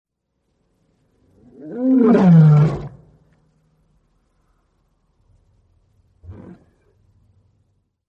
Lion Roar Téléchargement d'Effet Sonore
Lion Roar Bouton sonore